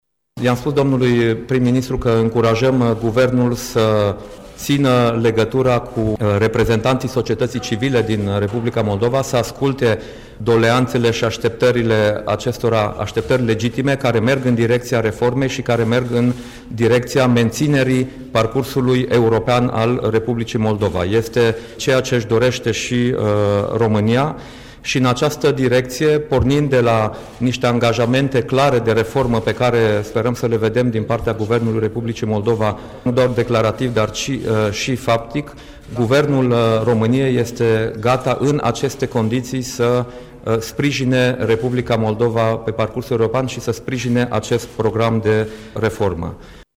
La finalul conferinței de presă de astăzi de la Palatul Victoria, premierul Dacian Cioloş l-a sfătuit pe omologul său moldovean, Pavel Filip, aflat în vizită la București, să ţină legătura permanentă cu reprezentanţii societăţii civile.
Premierul Dacian Cioloș: